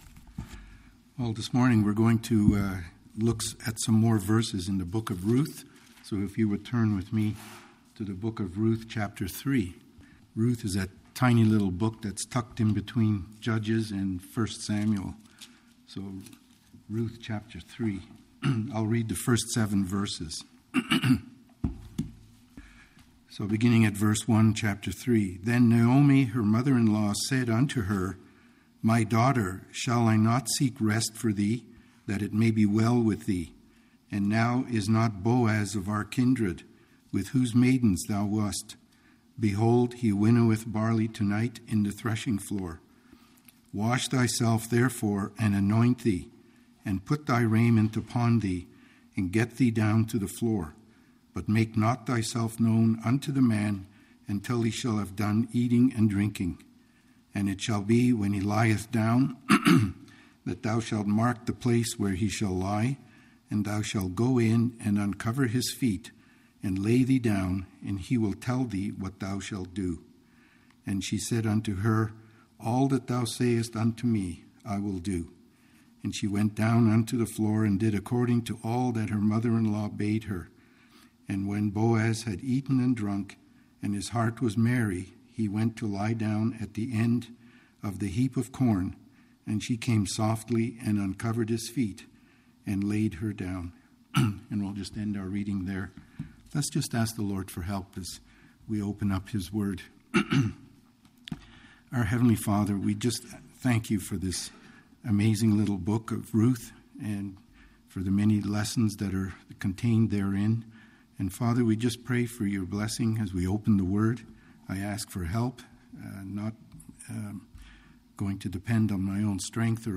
Bible study in the book of Ruth.
Ruth 3:3 & 4 Service Type: Mid week Bible study in the book of Ruth.